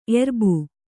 ♪ erbu